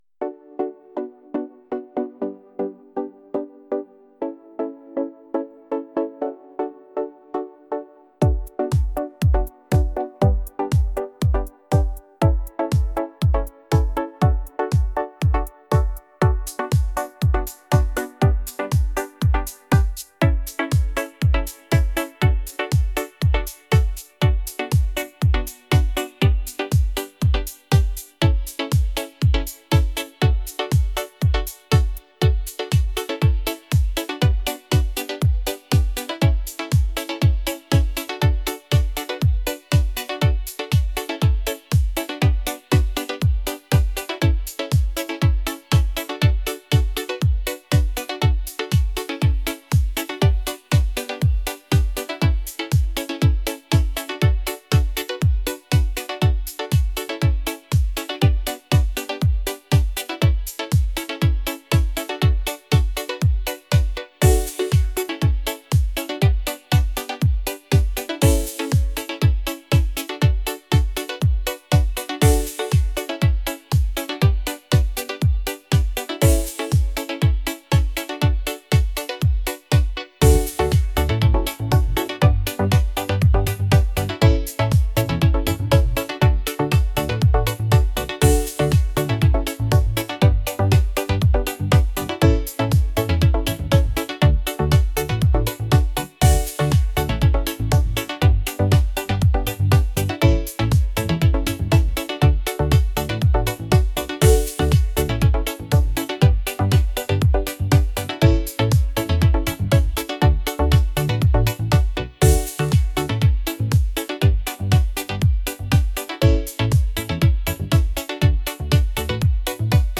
electronic | pop | upbeat